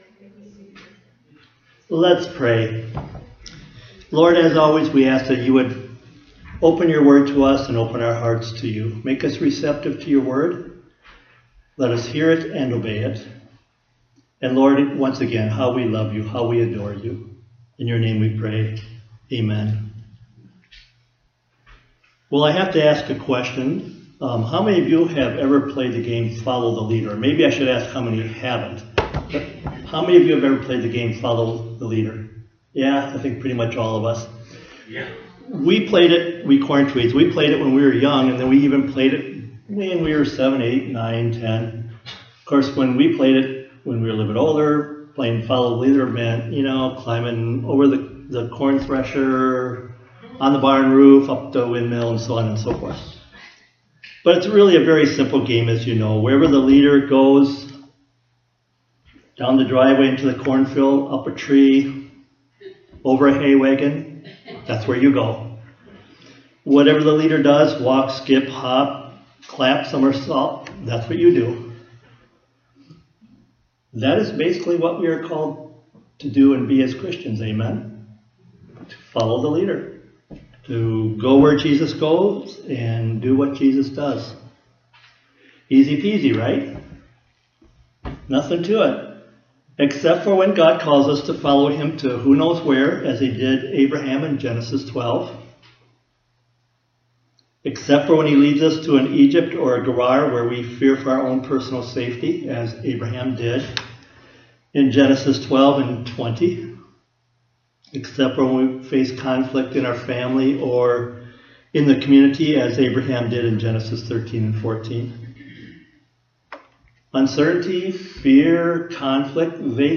Sermons | Westview Primitive Methodist Church